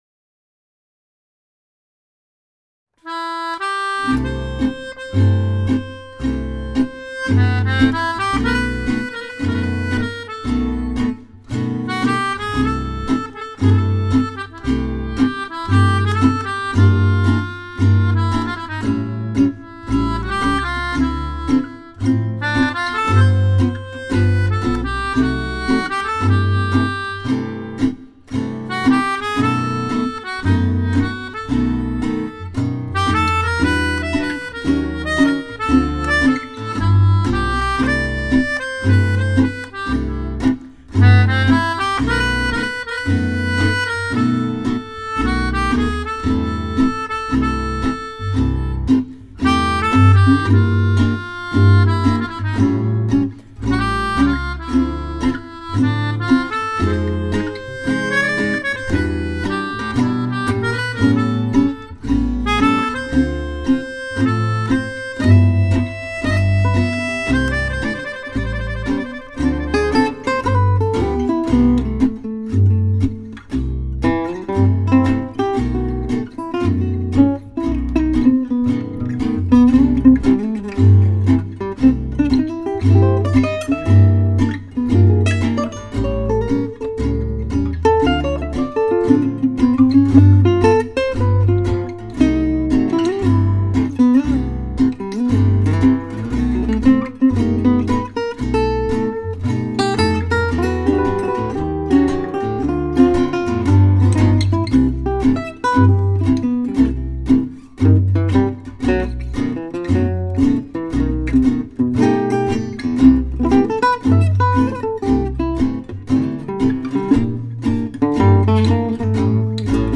And the melodica playing sounds great as well!
No, that's my old EKO fretless acoustic bass.
The guitar sounds really fantastic.